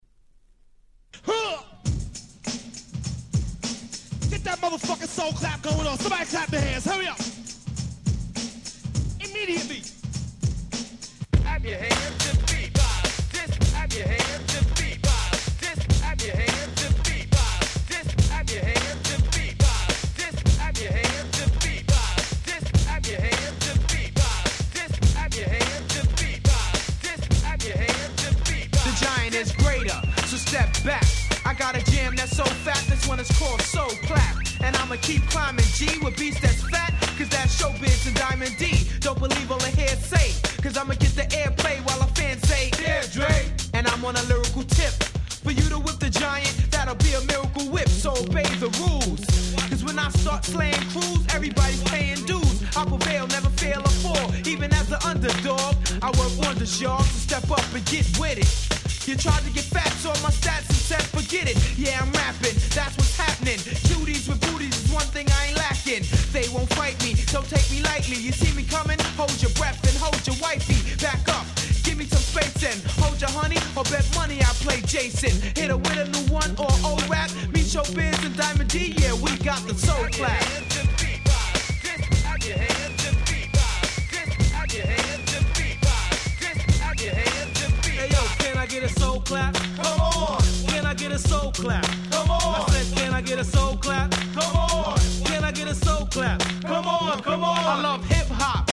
Boom Bap ブーンバップ